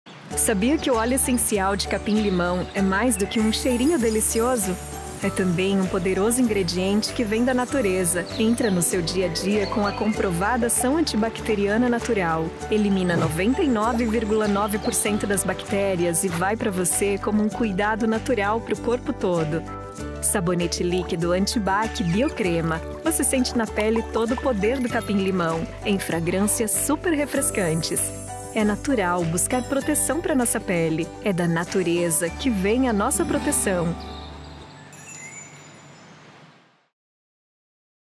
Narration
I have an equipped home studio, including a quality acoustic booth, which guarantees recordings with maximum audio excellence.
Microfone Audio-Technica ATR 2500
Cabine de locução com tratamento acústico.
Mezzo-Soprano
NaturalAffectionateCorporateJovialFriendlyAssuredNeutralSophisticated